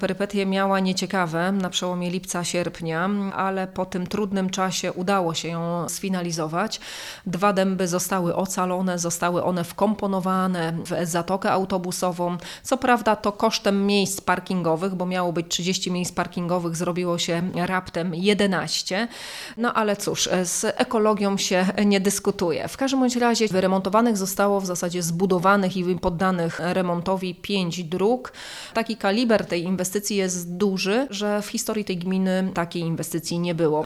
– Drzewa zostały, a historyczne zadanie zostało pomyślne zakończone – powiedziała Izabela Bojko, wójt gminy wiejskiej Nowa Sól: